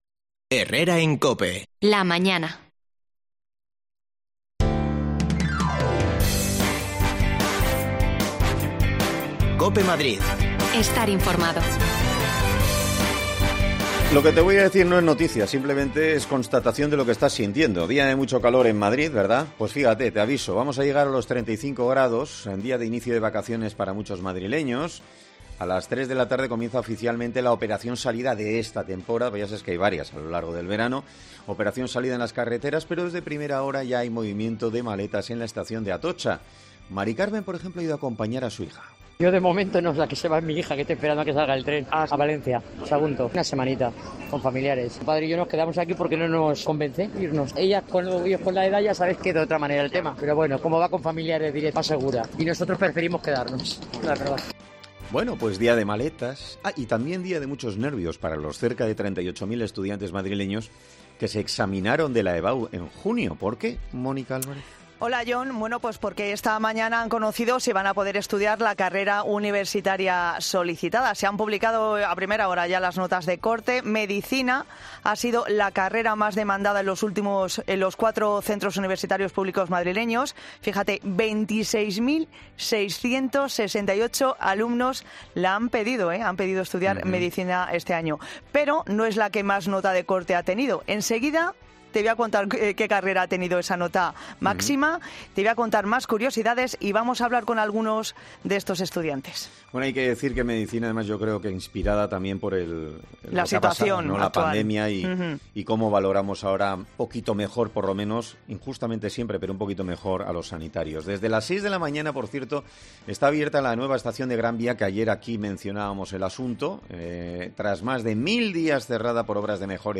A las 15:00 comienza oficialmente la operación salida en las carreteras. Preguntamos en la estación de Atocha a dónde se van y si sus planes han cambiado por culpa del Covid-19.